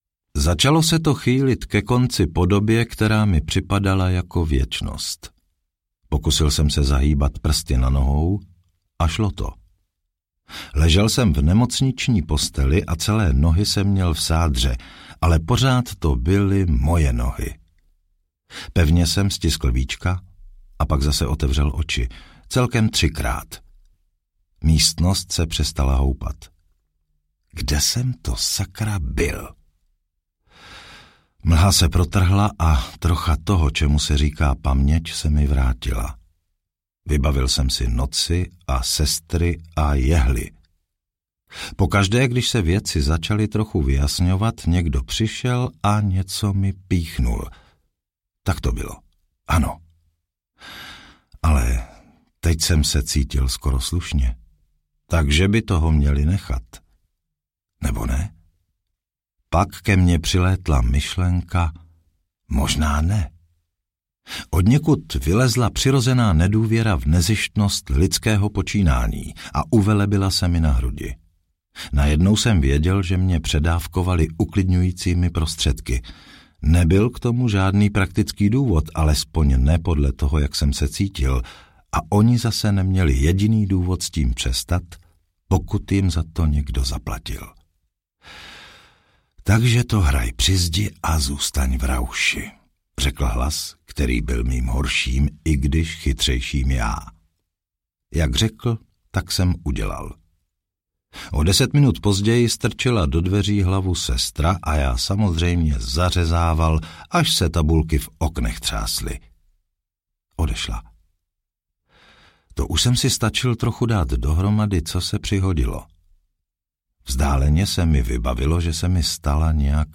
Amber 1 - Devět princů Amberu audiokniha
Ukázka z knihy